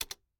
pause-back-click.ogg